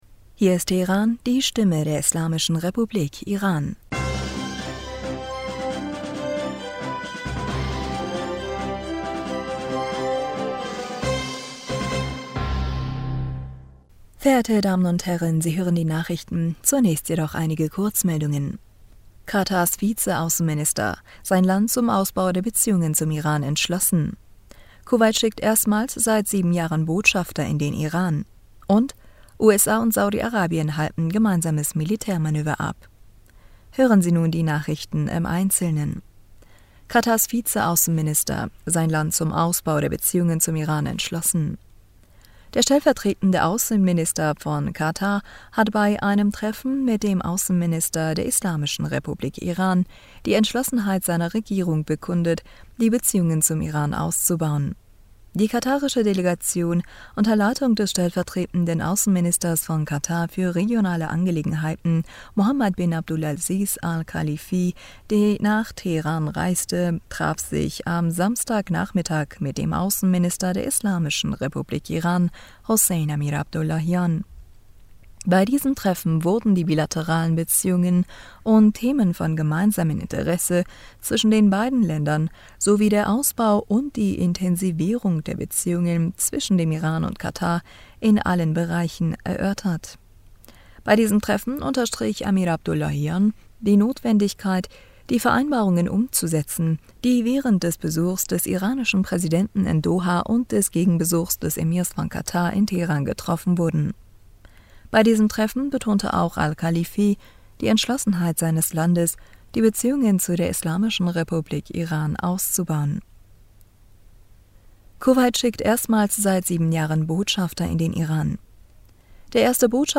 Nachrichten vom 14. August 2022